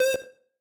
synth3_17.ogg